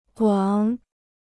广 (guǎng) Free Chinese Dictionary